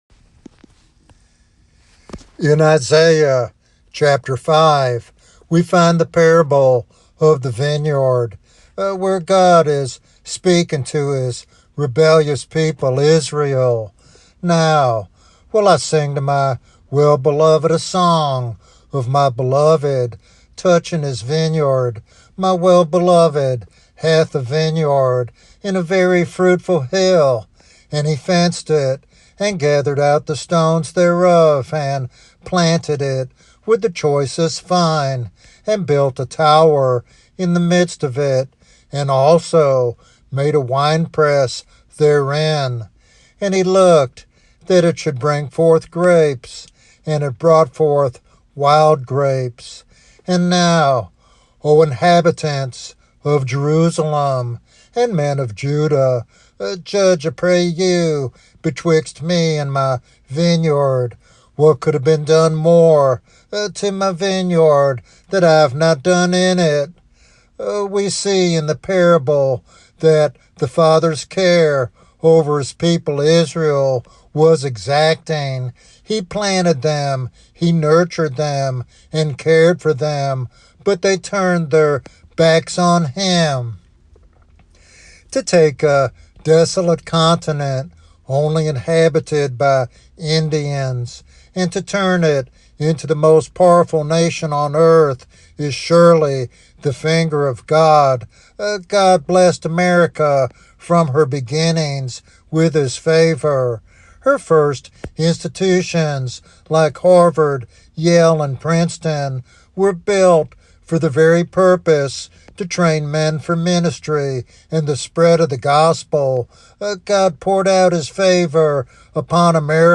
The sermon is based on Isaiah chapter 5, specifically the parable of the vineyard.